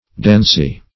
dancy - definition of dancy - synonyms, pronunciation, spelling from Free Dictionary Search Result for " dancy" : The Collaborative International Dictionary of English v.0.48: Dancy \Dan"cy\, a. (Her.)
dancy.mp3